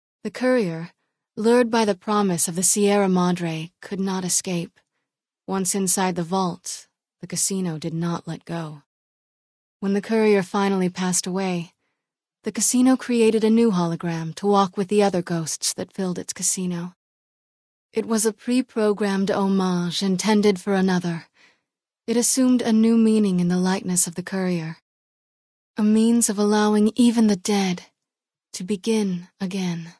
Category:Dead Money endgame narrations Du kannst diese Datei nicht überschreiben. Dateiverwendung Die folgende Seite verwendet diese Datei: Enden (Dead Money) Metadaten Diese Datei enthält weitere Informationen, die in der Regel von der Digitalkamera oder dem verwendeten Scanner stammen.